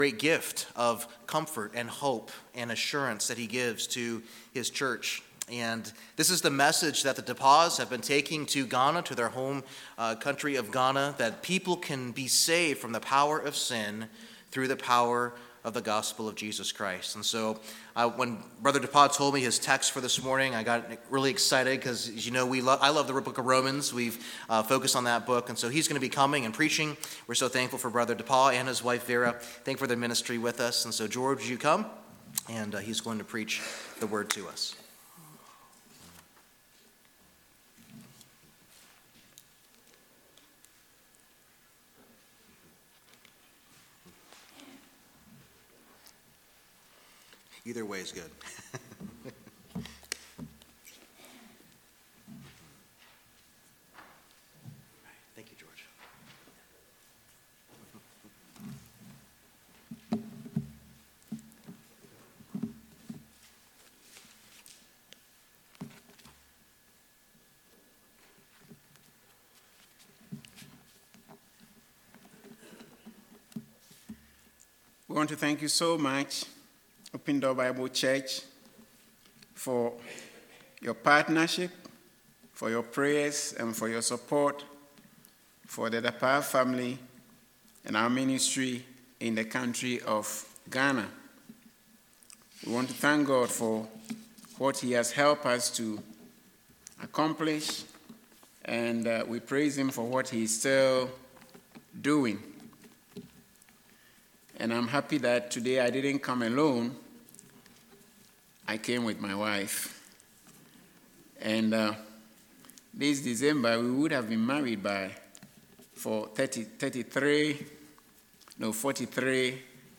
Sermons | Open Door Bible Church